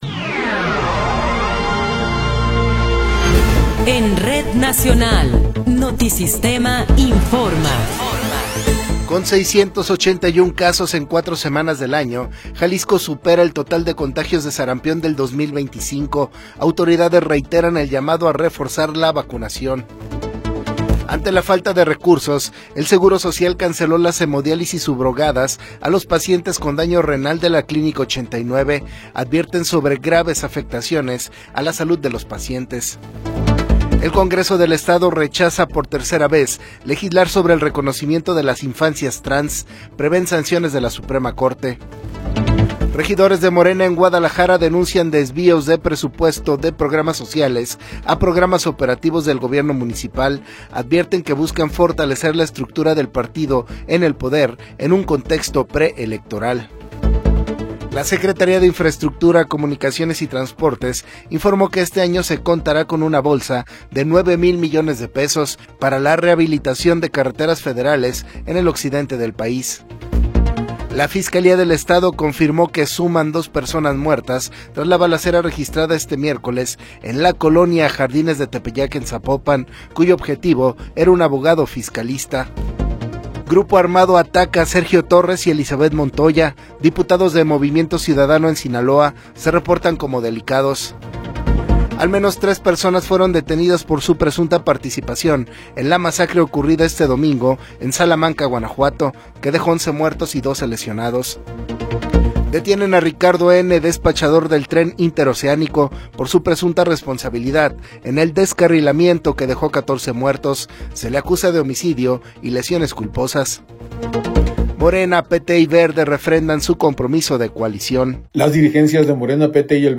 Noticiero 21 hrs. – 28 de Enero de 2026
Resumen informativo Notisistema, la mejor y más completa información cada hora en la hora.